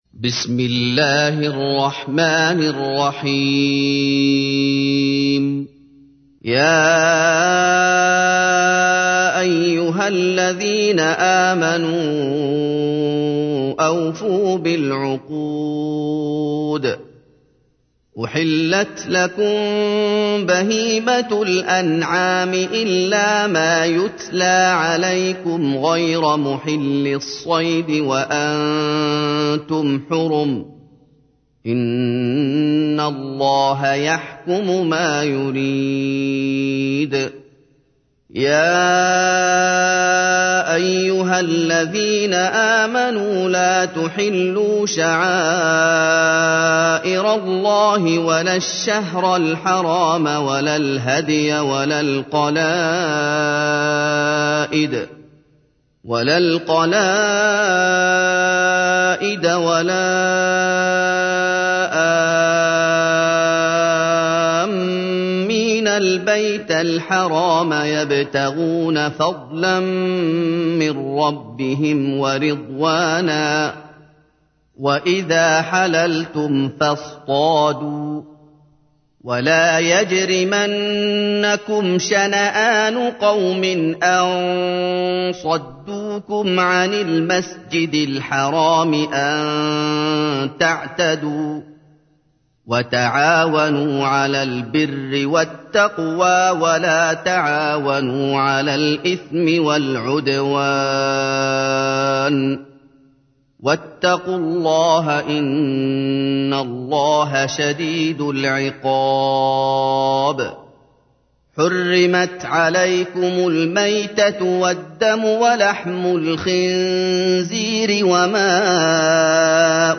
تحميل : 5. سورة المائدة / القارئ محمد أيوب / القرآن الكريم / موقع يا حسين